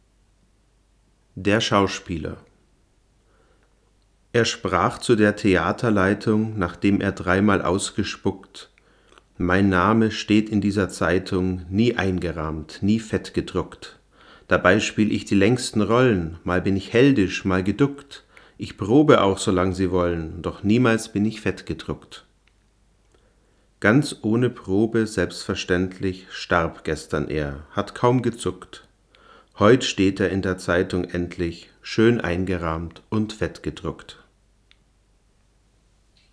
Ich habe jetzt noch eine weitere Reihe gemacht, diesmal mit dem Rode NTG-2 aus ebenfalls ca. 60 cm Abstand.
Mikrofon am Mixer Behringer MX802A (dann über Line-Eingang in Tascam DR-40)
behringer_mx802a.mp3